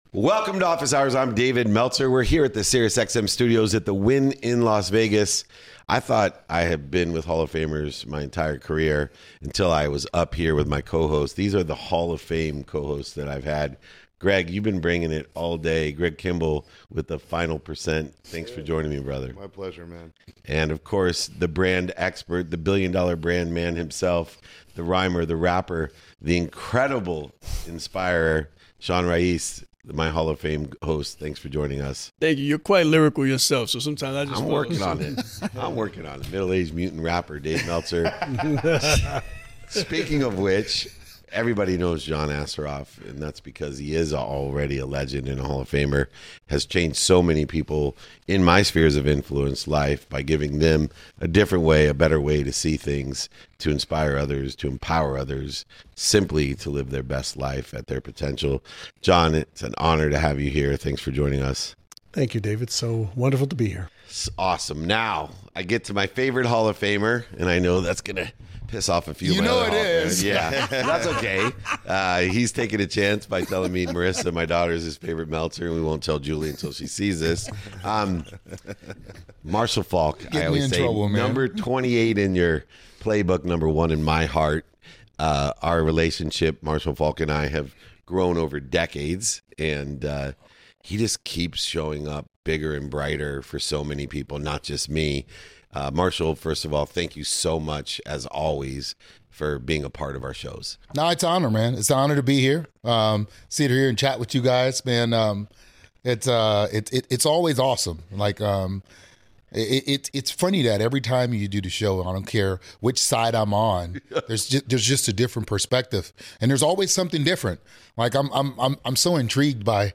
In today’s episode, I sit down with Hall of Famer Marshall Faulk for a powerful conversation on confidence, leadership, and resilience. Marshall shares the story of losing the Heisman Trophy, the lesson his mother taught him that night, and how it shaped his character for life. We talk about his ability to ask boldly in service of others, the mindset that made him one of the smartest players in football, and the preparation that still drives him today.